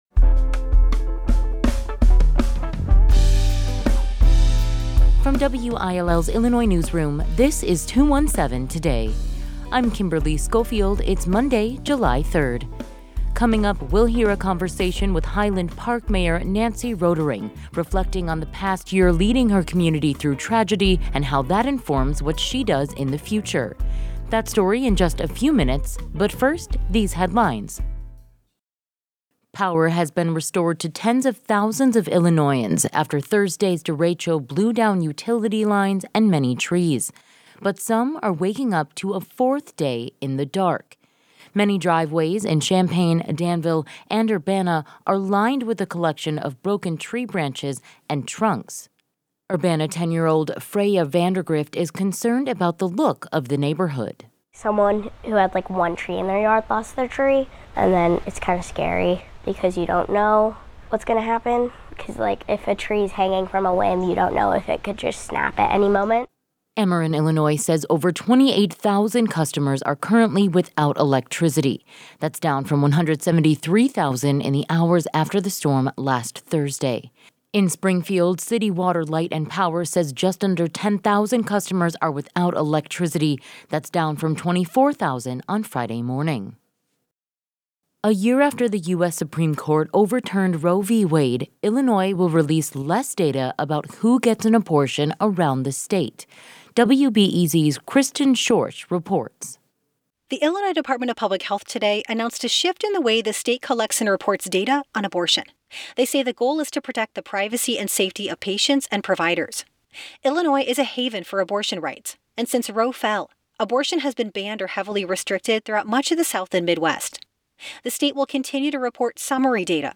In today’s deep dive, we’ll hear a conversation with Highland Park Mayor Nancy Rotering reflecting on the past year leading her community through tragedy and how that informs what she does in the future.